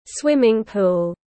Bể bơi tiếng anh gọi là swimming pool, phiên âm tiếng anh đọc là /ˈswɪm.ɪŋ ˌpuːl/.